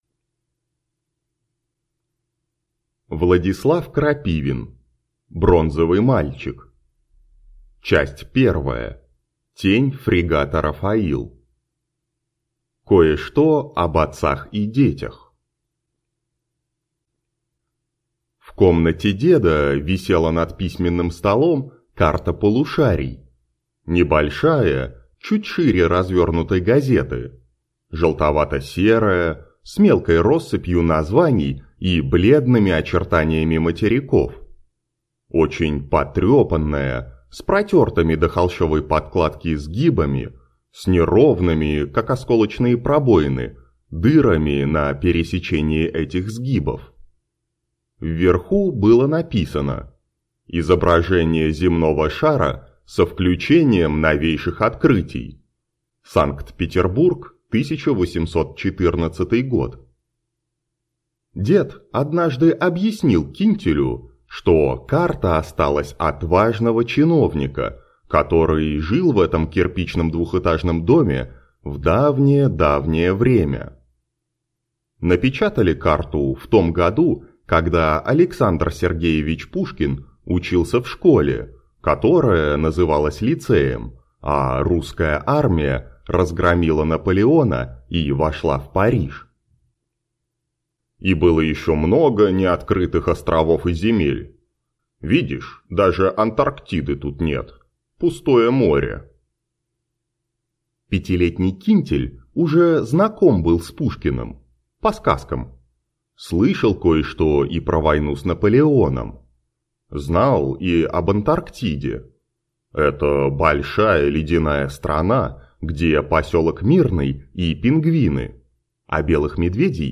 Аудиокнига Бронзовый мальчик | Библиотека аудиокниг